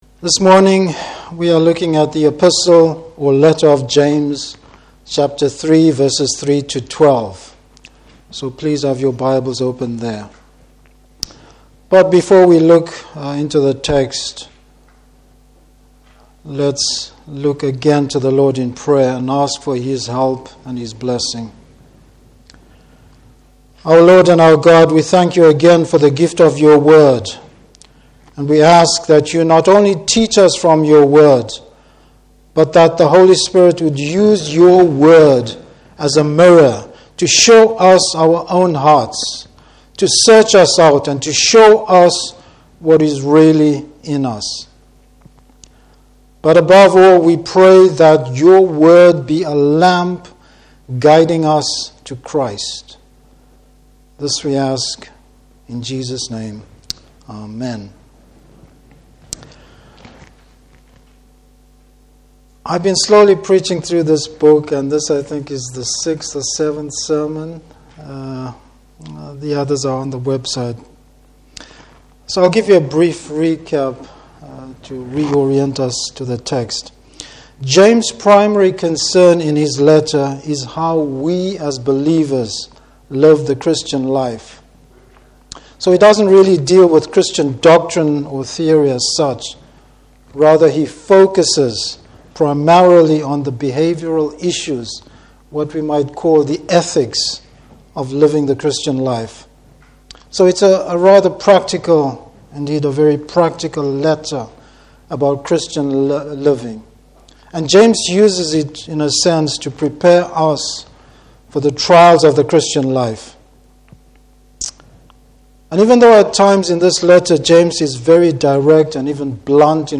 Service Type: Morning Service The insidious nature of the tongue.